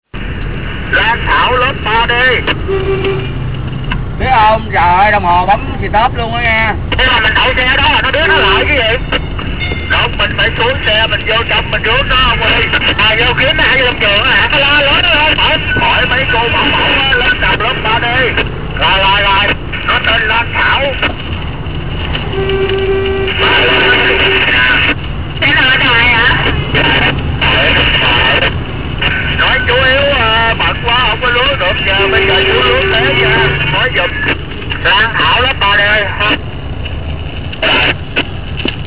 It was fun to take a cap once through the caotic traffic of Saigon with a cab driver that had nothing better to do then blow his horn all the time and shouted into his walky-talky.